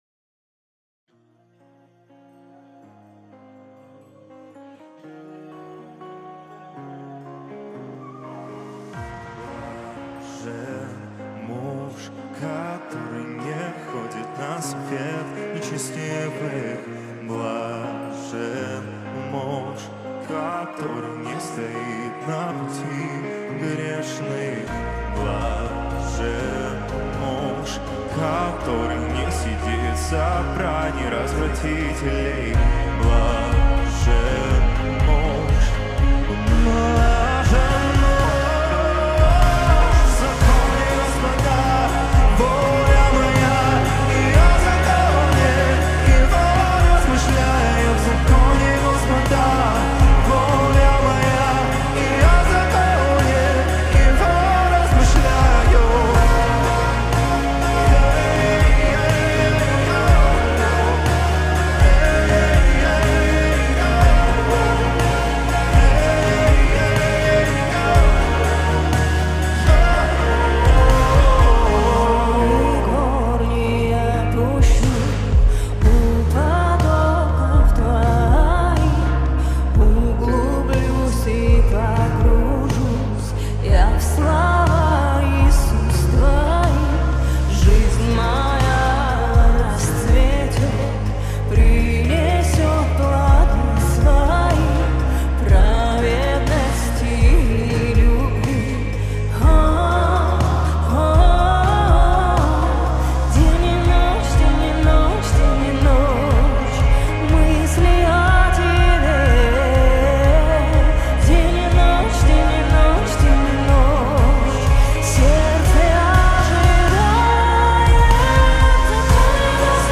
песня
344 просмотра 401 прослушиваний 53 скачивания BPM: 120